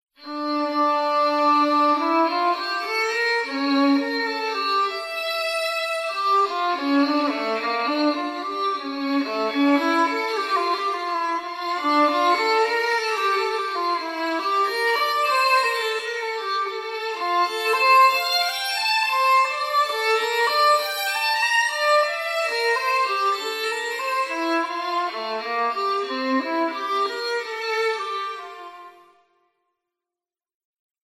All samples are the same 31 seconds from a violin piece by Bach, recorded in anechoic conditions, but convolved with different IRs. Headphones required.
2013_cha_Purple_Bach_violin.mp3